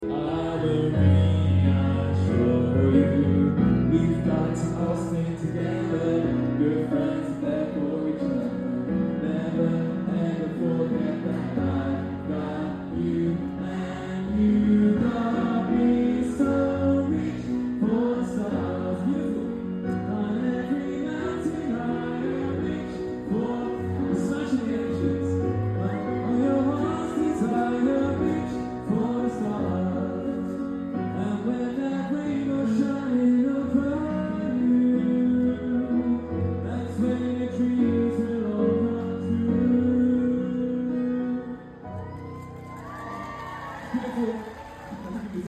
What a beautiful festival and an amazing setting at Lulworth Castle down the road from where I grew up in Poole.